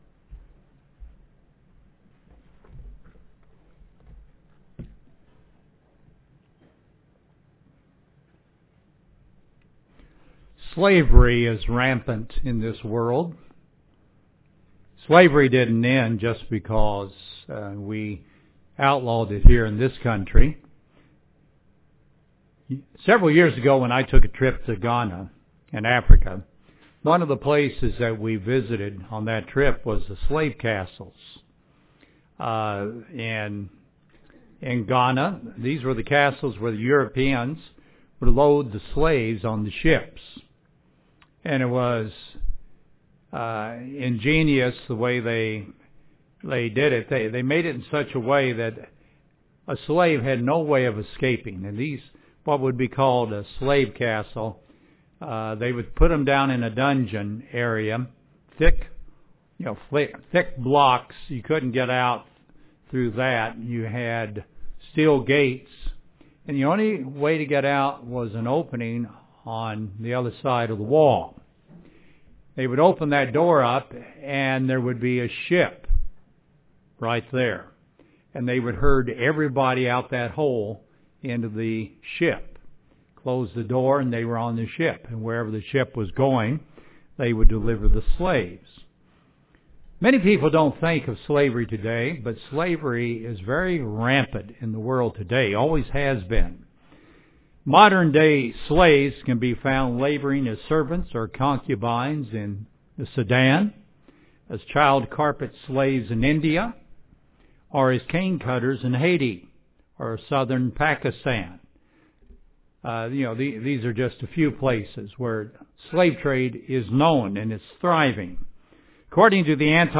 At baptism, we agreed to be a servant to God forever. This sermon shows the kind of heart and attitude we should have.